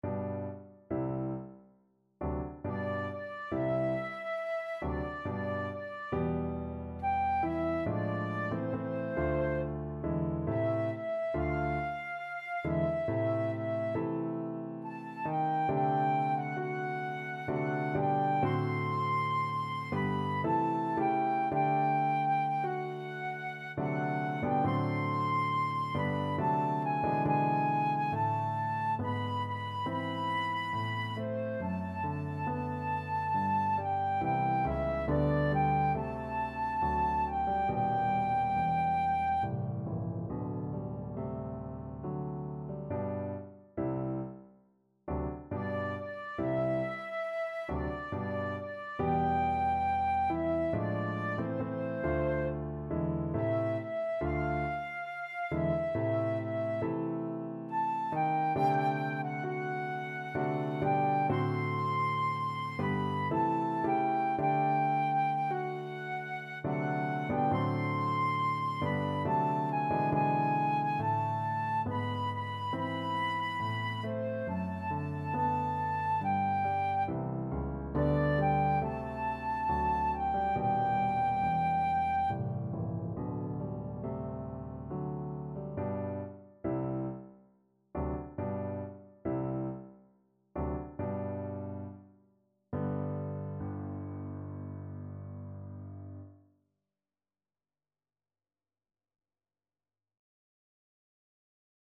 Classical Schumann, Robert Seit ich ihn gesehen (No. 1 from Frauenliebe und Leben) Flute version
Flute
3/4 (View more 3/4 Music)
Larghetto =69
G major (Sounding Pitch) (View more G major Music for Flute )
Classical (View more Classical Flute Music)